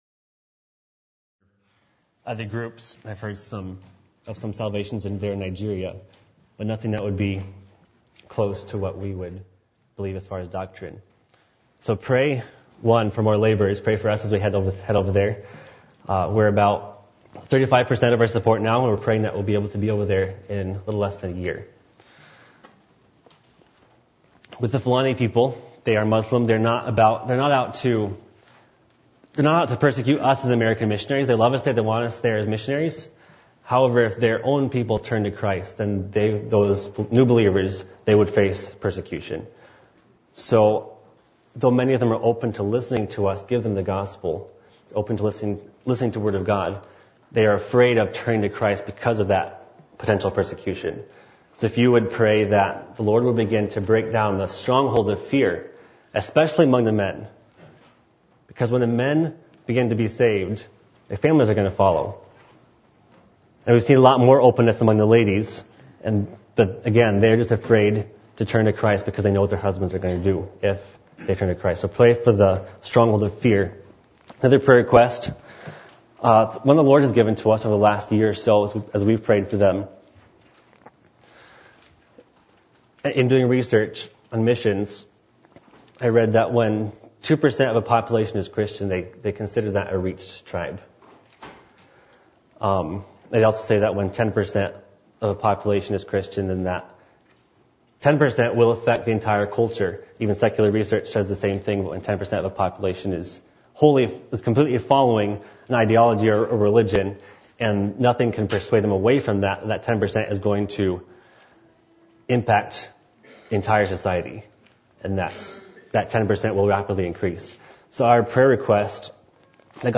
Matthew 28:18-20 Service Type: Midweek Meeting %todo_render% « Lessons On Bitterness From Joseph’s Family